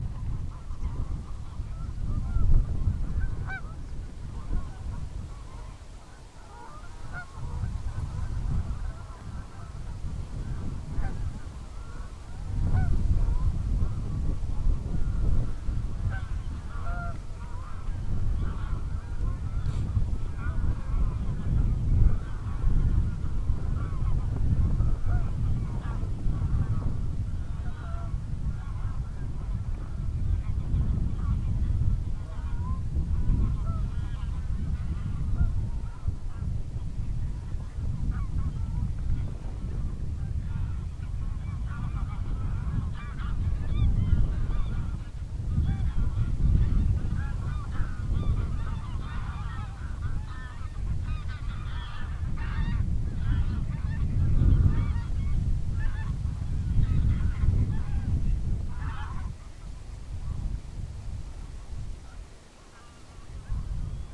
Ambient sound
skandinavien_store_mosse_nationalpark_3_gaense_wind_edit.mp3